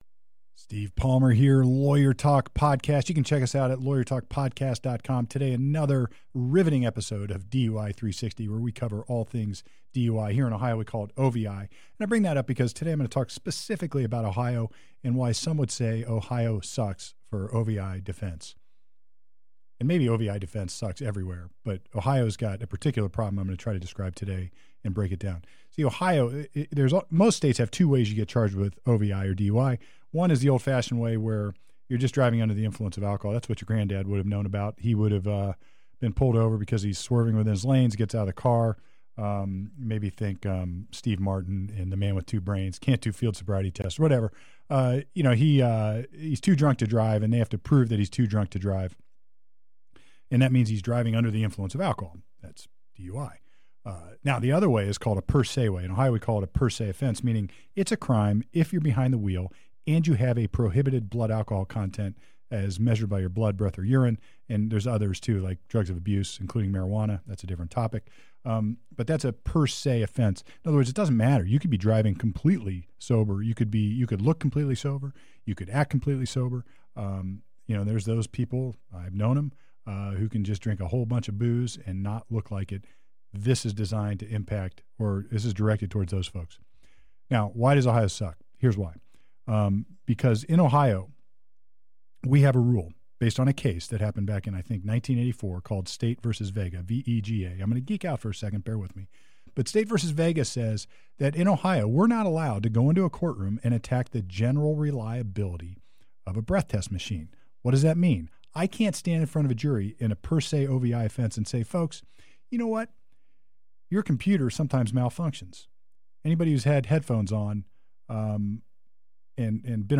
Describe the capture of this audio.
Recorded at Channel 511.